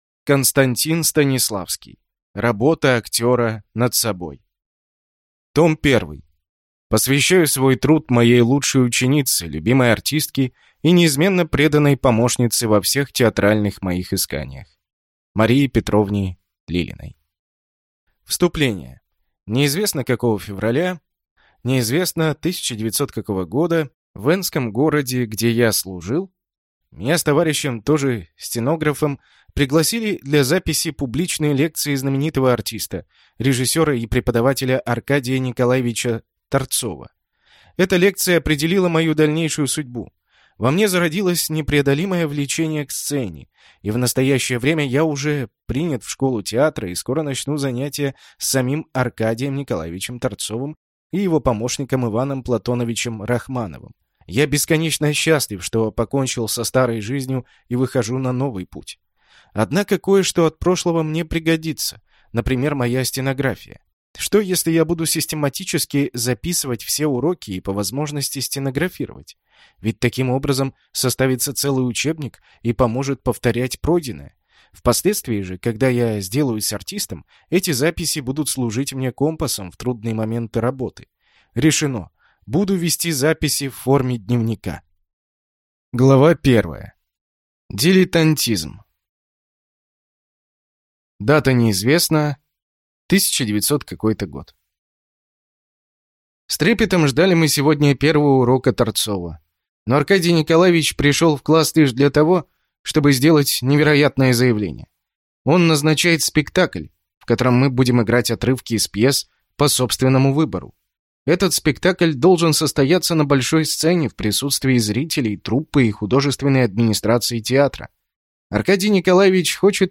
Аудиокнига Работа актера над собой | Библиотека аудиокниг